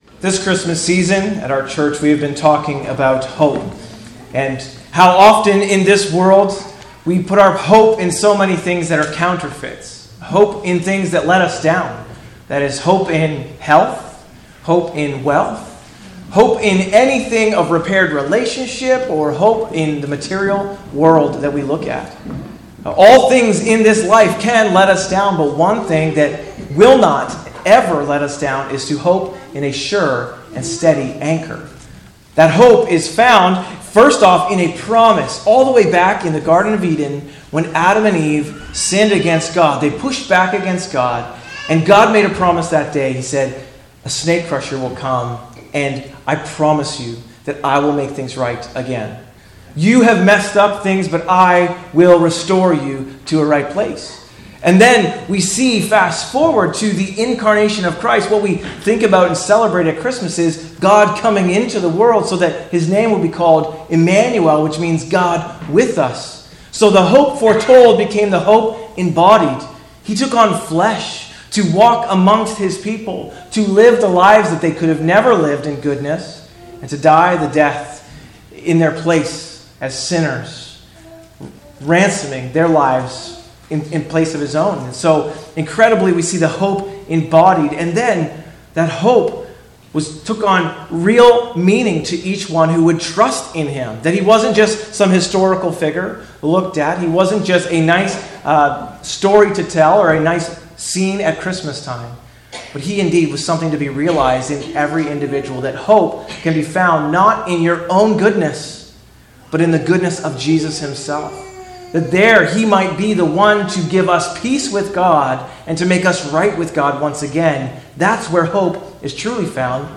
A message from the series "Christ Our Hope."